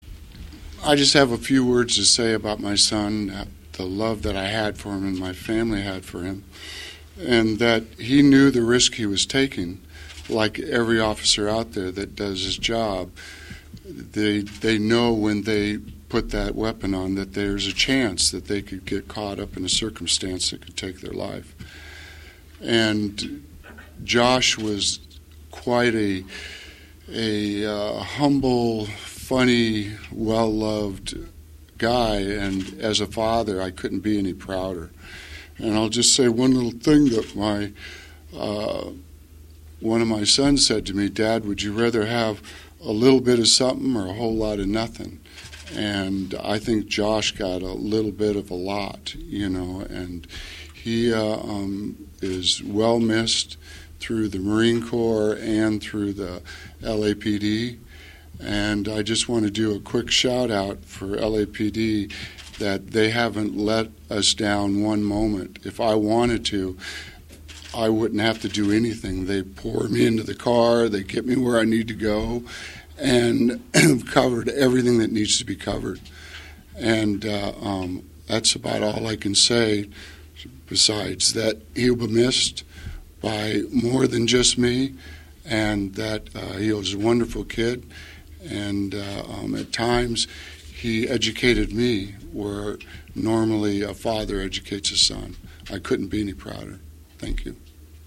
speaking to the media at a news conference held before the start of the memorial ceremony.